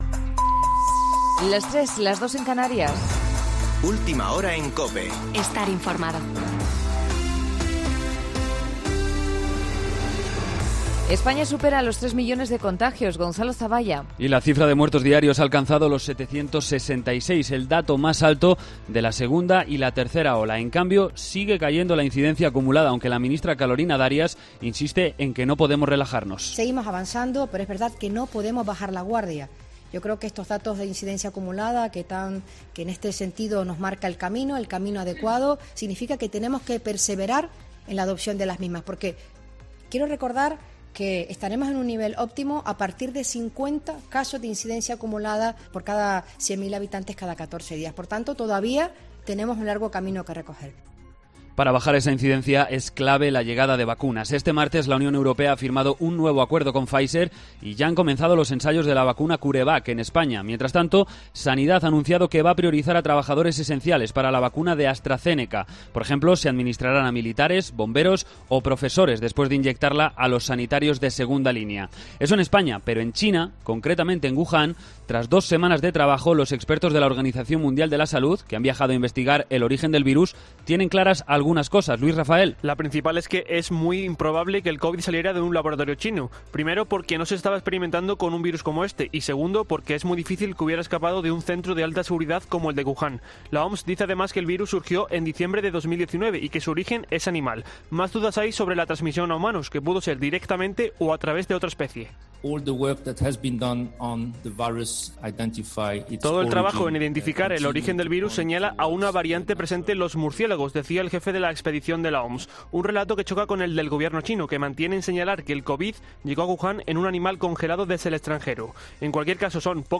Boletín de noticias COPE del 10 de febrero de 2021 a las 03.00 horas